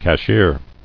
[cash·ier]